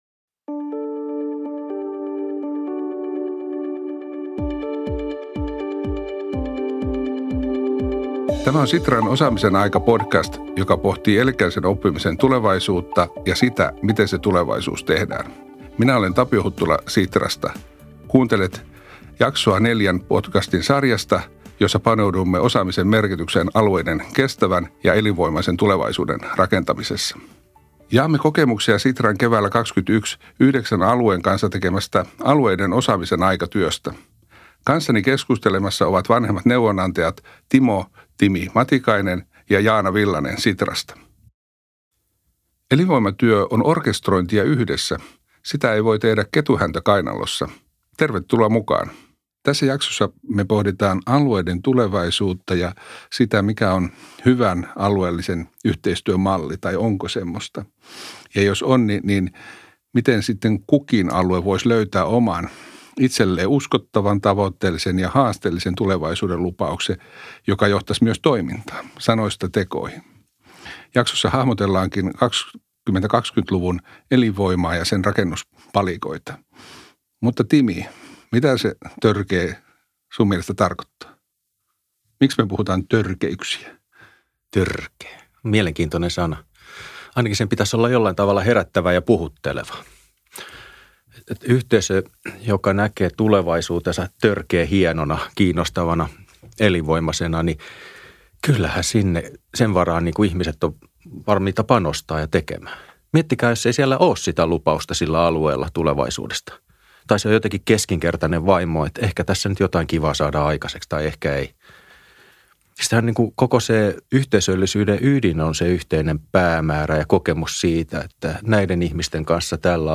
Keskustelua ja tehtyä työtä kommentoi jaksossa Pirkanmaan maakuntajohtaja Esa Halme.